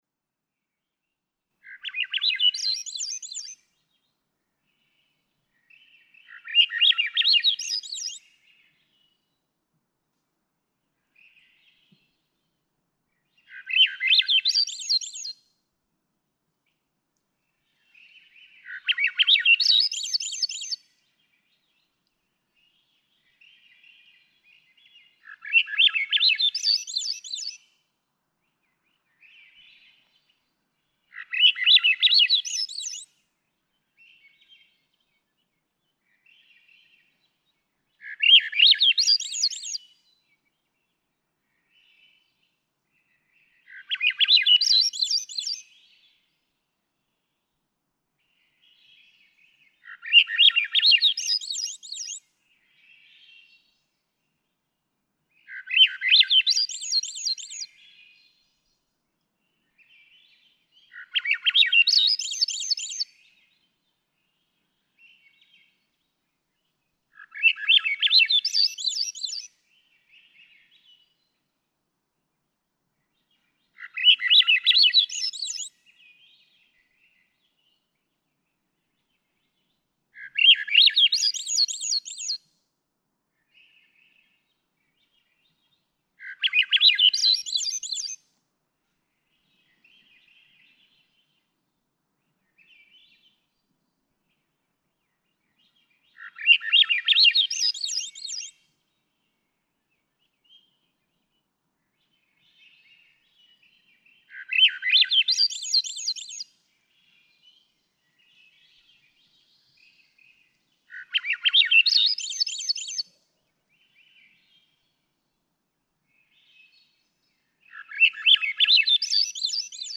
Swainson's thrush
In the calm of an Alaskan summer night, on a stage only with other Swainson's thrushes, their songs rise to the heavens. 2:23 to 2:36 a.m. Sunrise at 3:34 a.m. June 18, 2017. Slana, Alaska.
659_Swainson's_Thrush.mp3